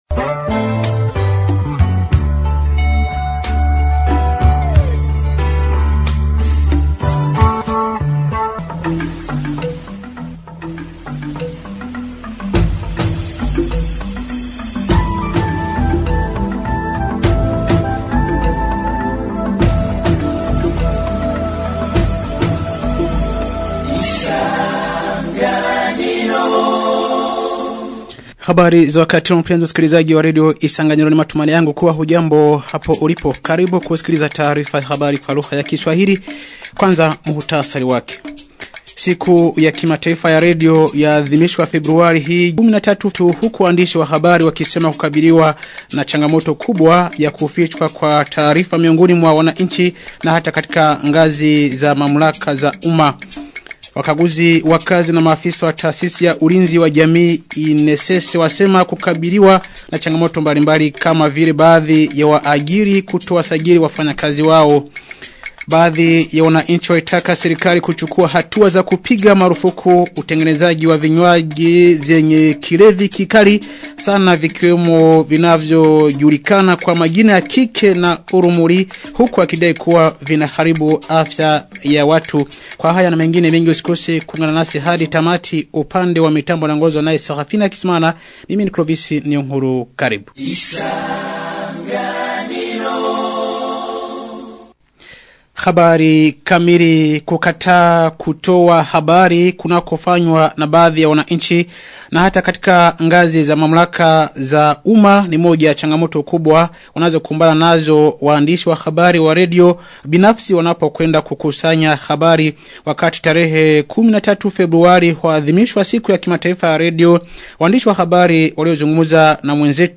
Taarifa ya habari ya tarehe 13 Februari 2026